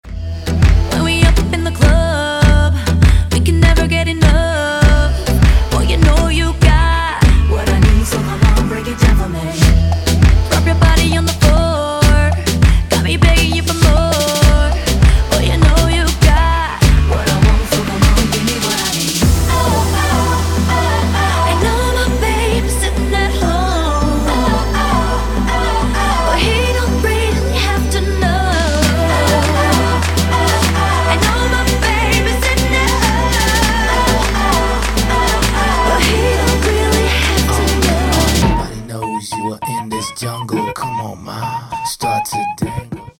• Качество: 192, Stereo
поп
женский вокал
dance
club
vocal